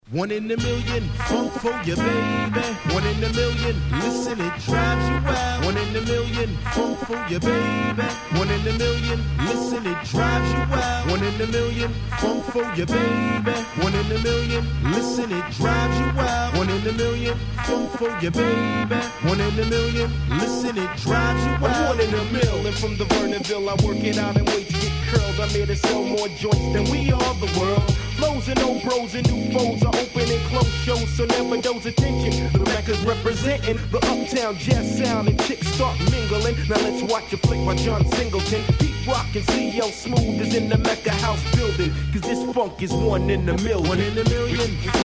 サントラはめちゃくちゃ音圧が低いのでPlayするなら絶対にこの盤でしょう！